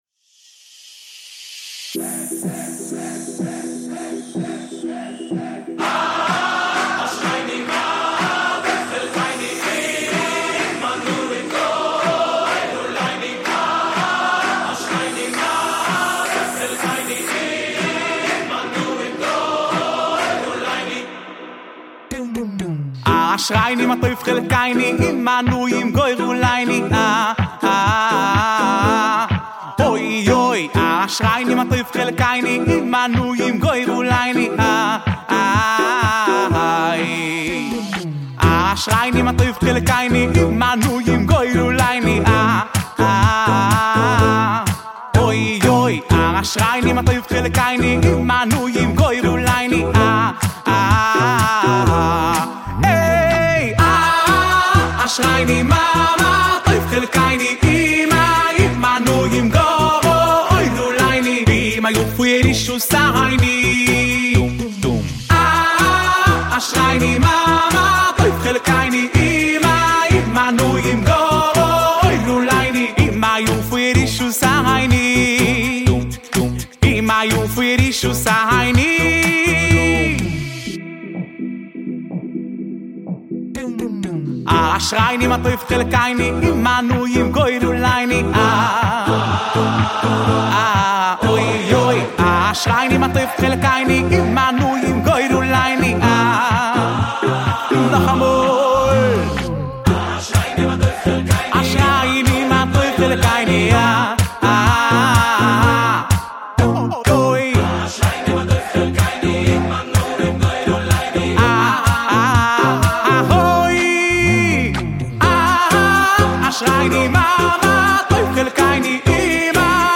ווקאלי מילים ולחן